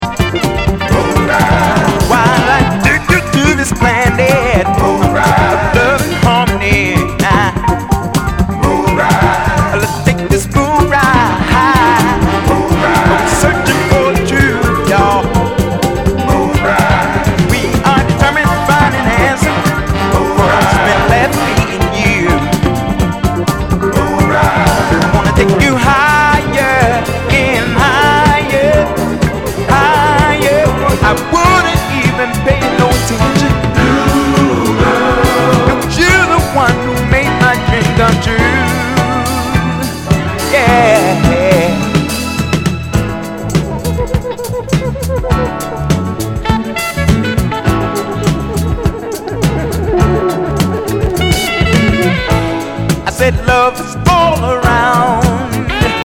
SOUL/FUNK/DISCO
ナイス！ディスコ・ファンク～ブギー!